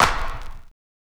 CLAP_WHISTLE2.wav